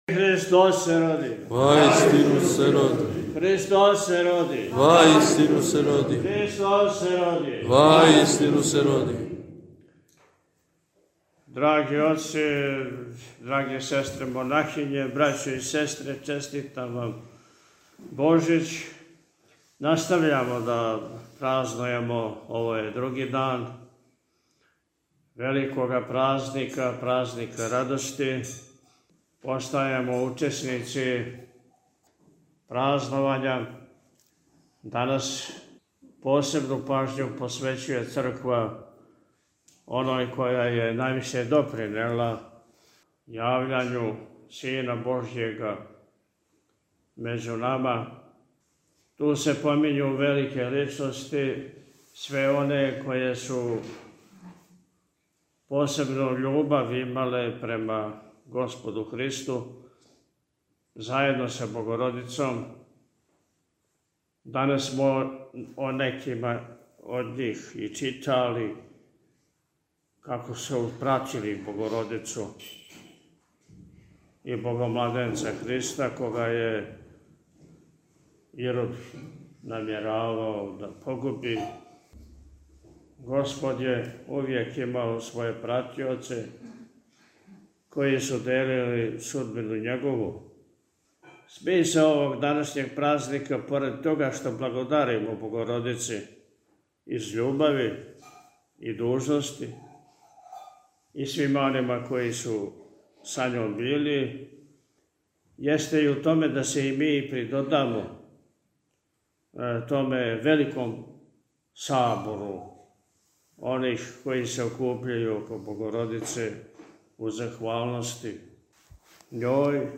Прослава другог дана Божића у манастиру Сељани - Eпархија Милешевска
Владика је у пастирској беседи, након отпуста, рекао: – Настављамо да празнујемо.